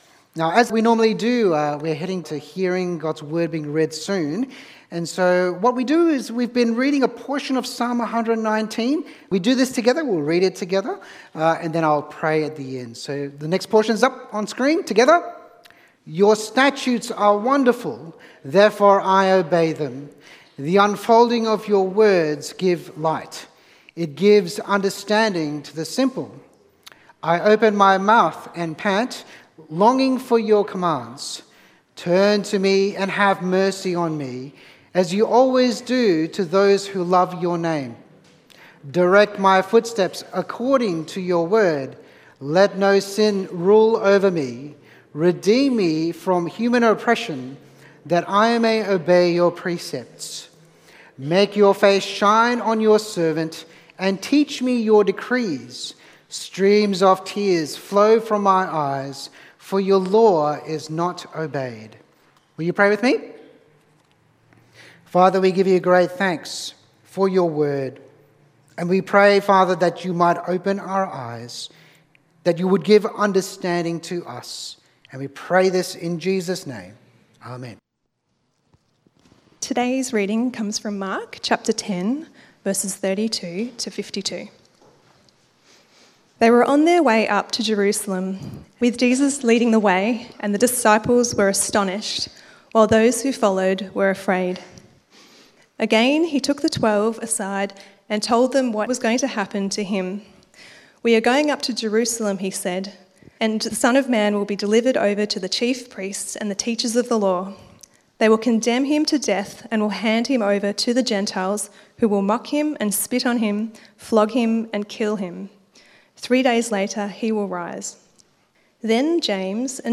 Meet Jesus Sermon outline